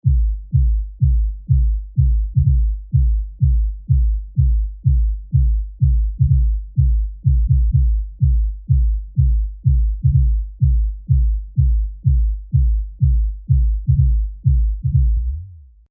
で、まずフィルターをかけてみました。
この状態ではフィルターは動きません。